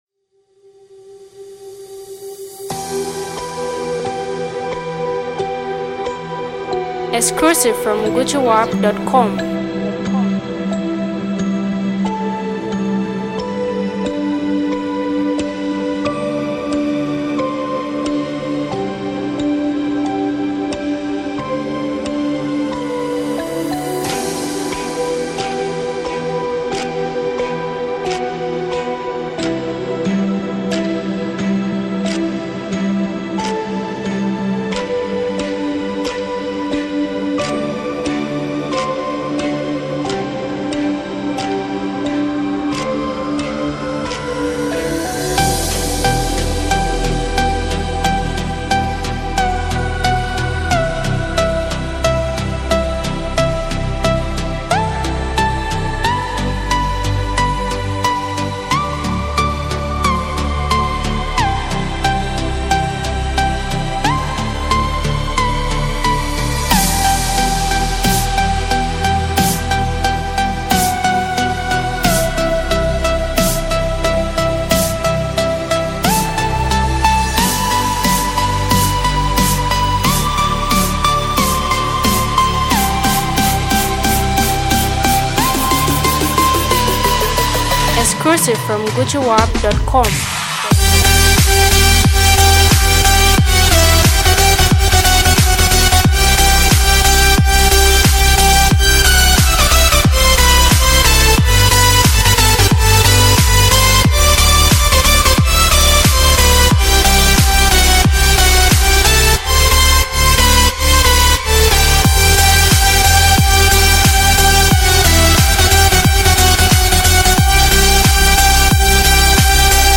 futuristic soundscapes